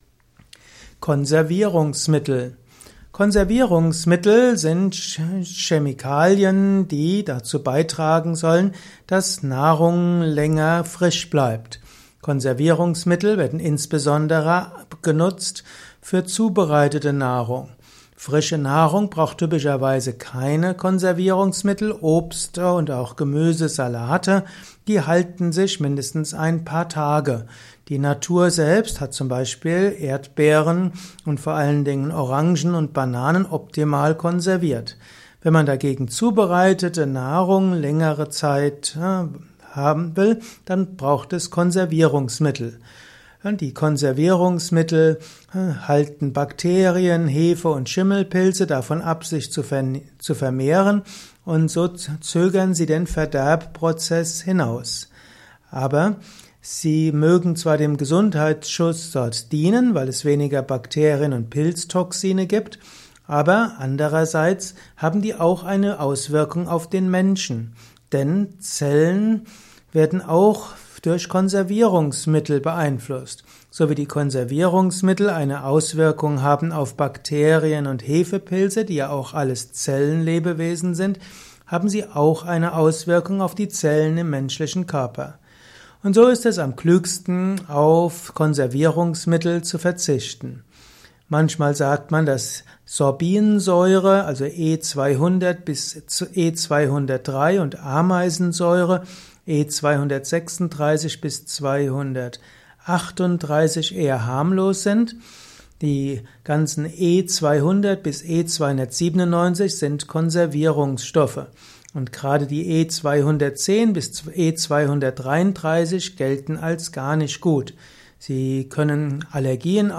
Simple und komplexe Infos zum Thema Konservierungsmittel in diesem Kurzvortrag